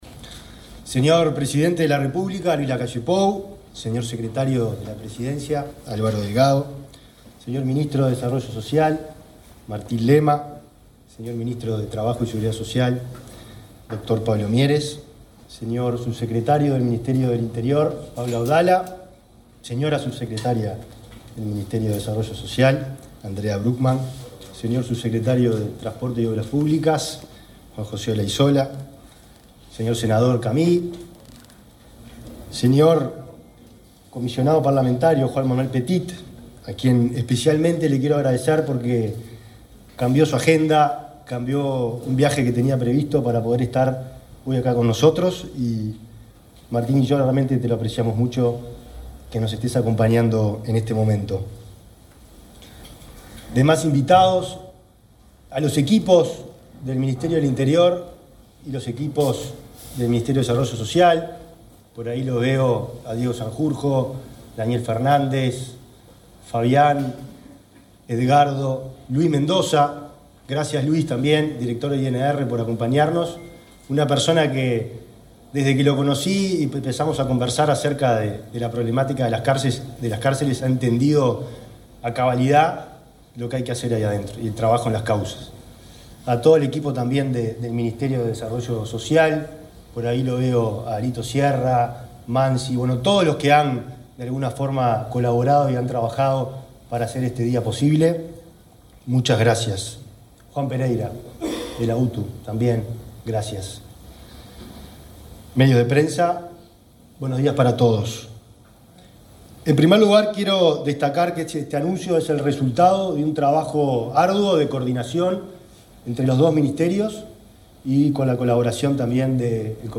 Palabras del ministro del Interior, Nicolás Martinelli
Este jueves 30, el ministro del Interior, Nicolás Martinelli, participó de la presentación del Proyecto de Inclusión Asistida (PIA), dirigido a la